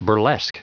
L'accent tombe sur la dernière syllabe: